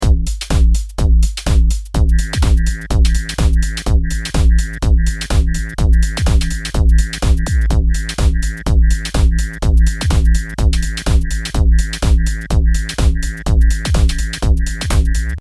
裏打ちになってるリバースの音がフィルターによって加工されています。
シンセっぽい音にも聞こえるし、パーカッション的な要素もあったりするし…ちょっと面白いですね。
この音の正体は、フィルターで“COMB（コム、昆布ではない）”スイッチを入れていることになります。